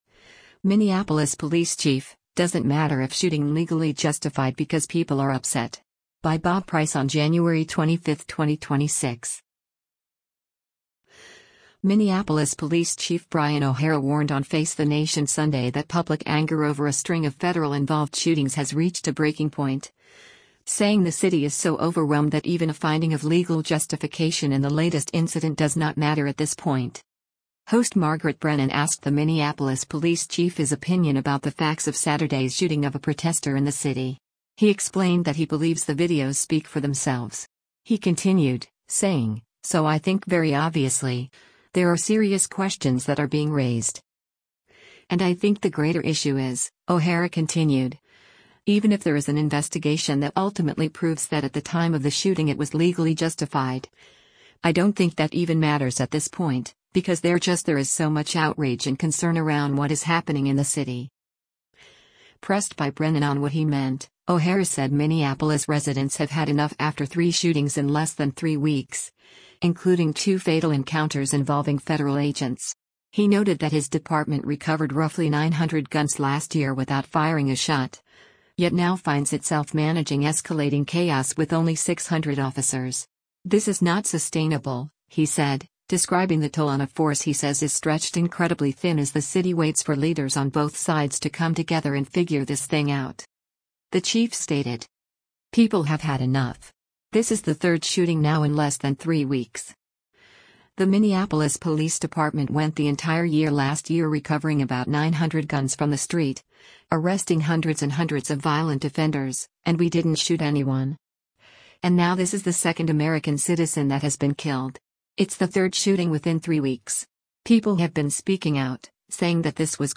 Minneapolis Police Chief Brian O’Hara warned on Face the Nation Sunday that public anger over a string of federal‑involved shootings has reached a breaking point, saying the city is so overwhelmed that even a finding of legal justification in the latest incident “does not matter at this point.”
From the CBS transcript of the interview: